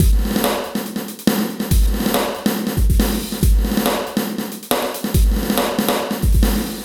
E Kit 25.wav